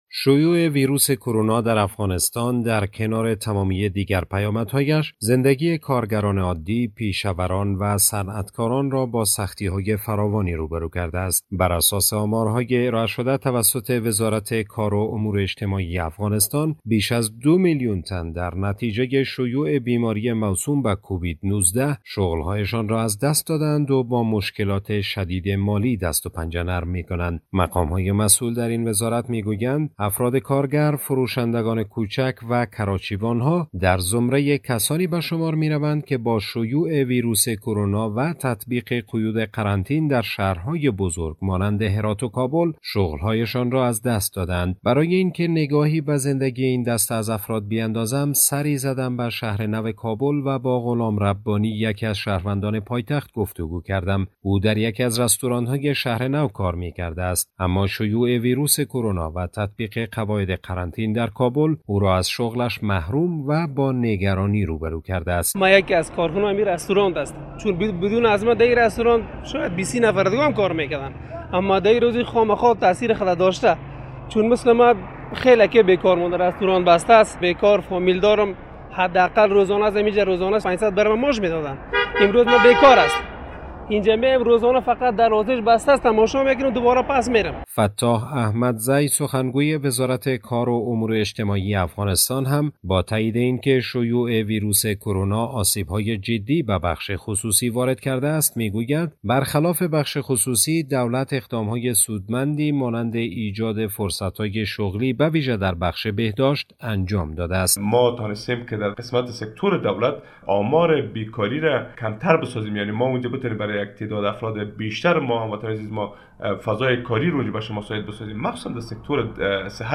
به گزارش خبرنگار رادیودری، براساس آمارهای ارایه شده توسط وزارت کار و امور اجتماعی افغانستان، بیش از دو میلیون تن در نتیجه شیوع بیماری موسوم به کووید نوزده شغل های شان را از دست داده اند و با مشکلات شدید مالی دست و پنجه نرم می کنند.